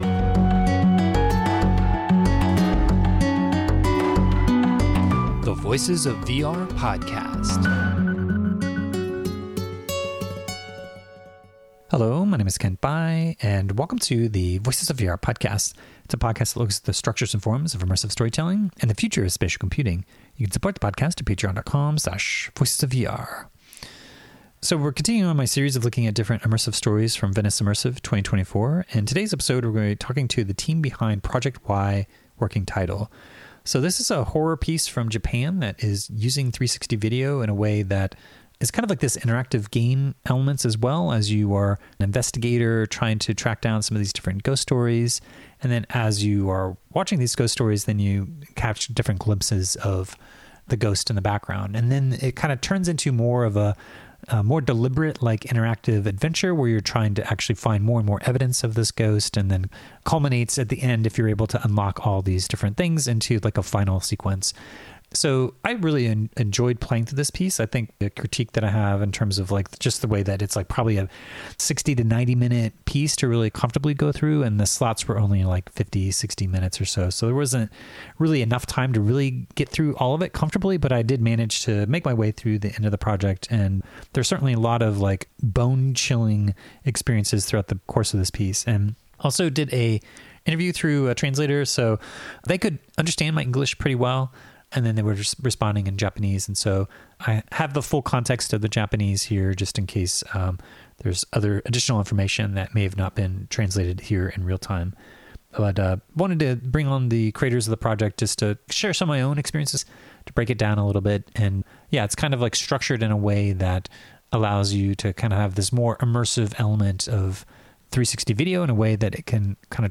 Also did a interview through a translator, so they could understand my English pretty well, and then they were responding in Japanese.